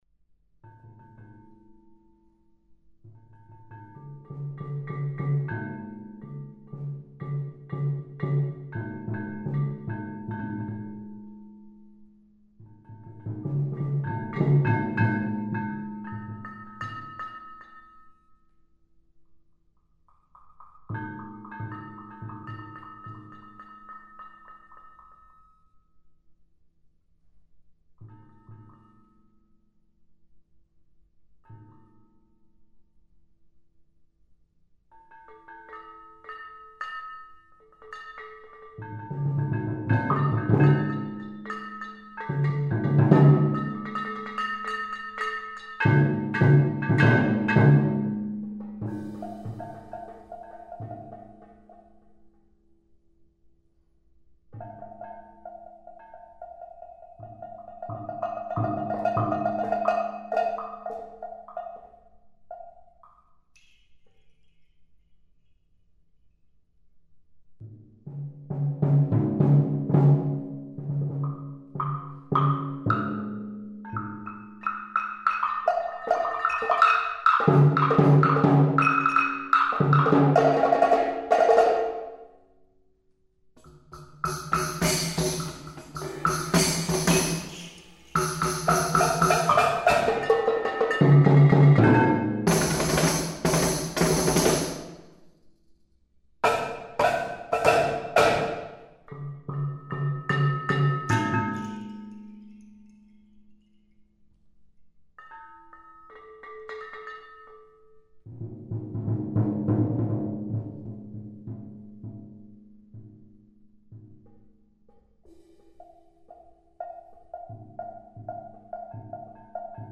modern classical music for percussion
for percussion duo